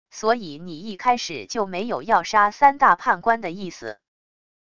所以你一开始就没有要杀三大判官的意思wav音频生成系统WAV Audio Player